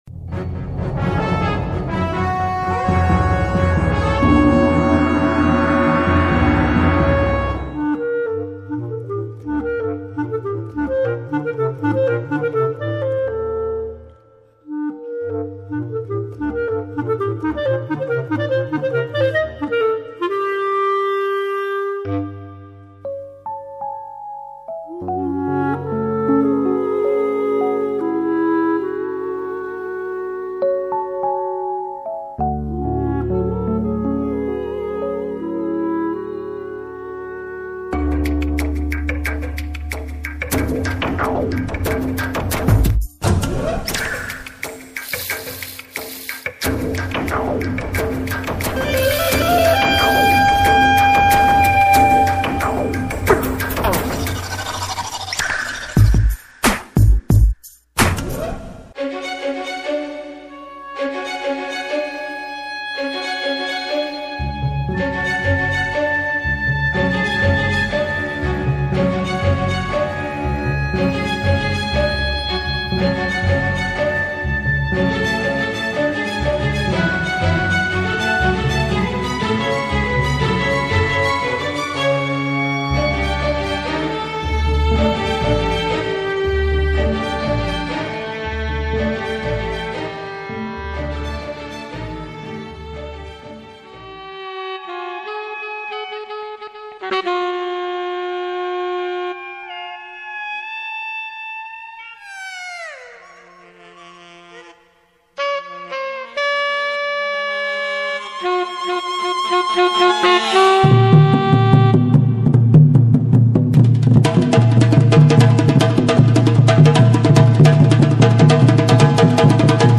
.Violon
Soprano
Guitare